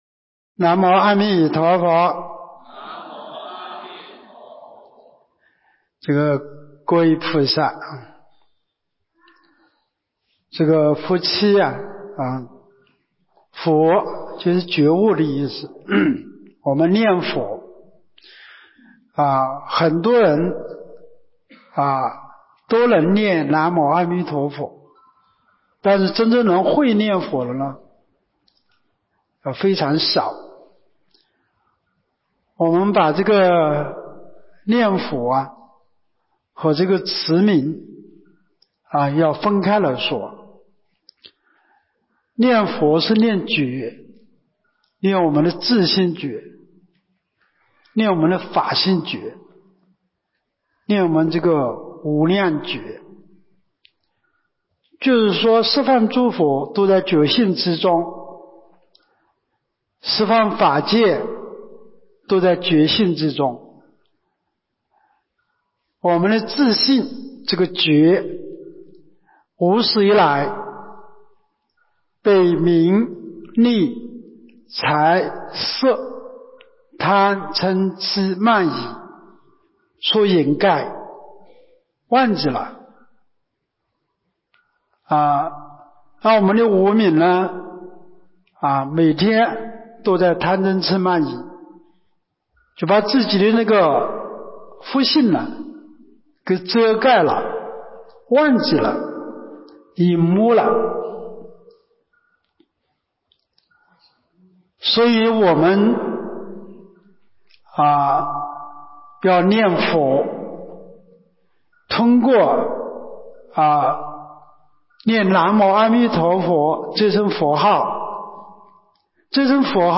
24年陆丰学佛苑冬季佛七（一）